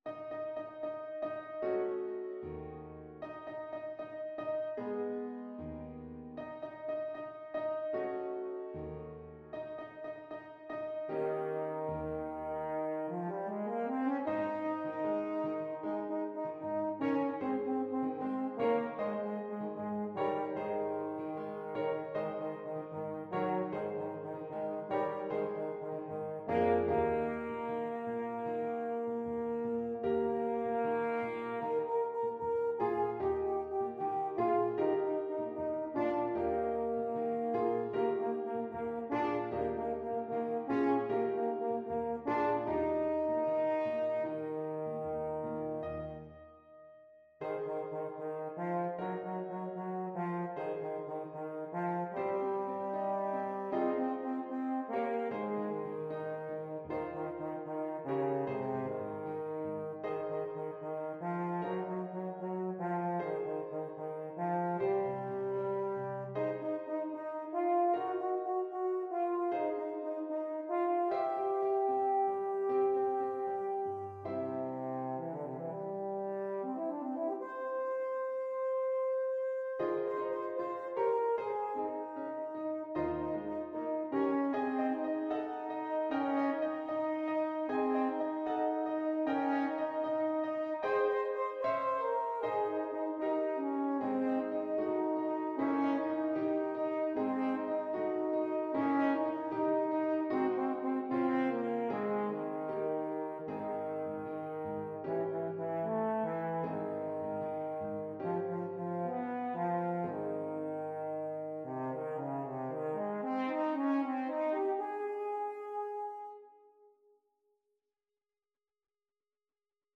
Classical Saint-Saëns, Camille Havanaise, Op.83 French Horn version
French Horn
Ab major (Sounding Pitch) Eb major (French Horn in F) (View more Ab major Music for French Horn )
2/4 (View more 2/4 Music)
=76 Allegretto lusinghiero =104
Classical (View more Classical French Horn Music)